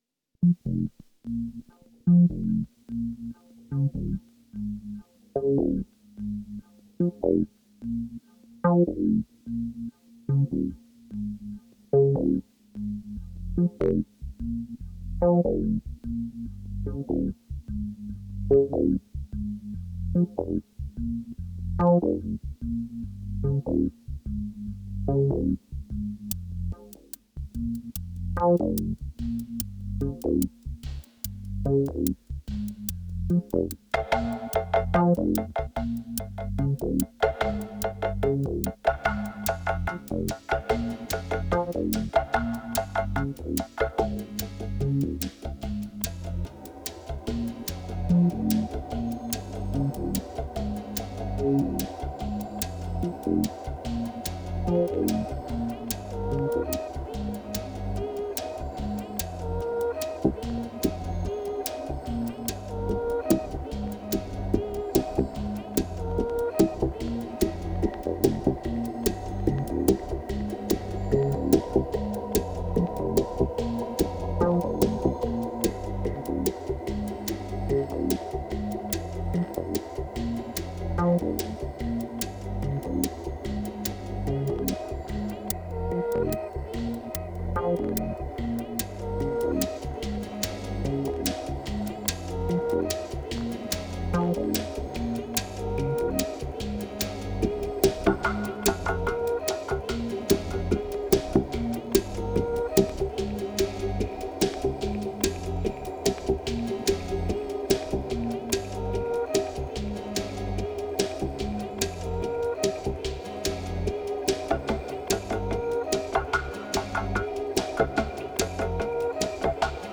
3028📈 - 28%🤔 - 73BPM🔊 - 2017-02-08📅 - -93🌟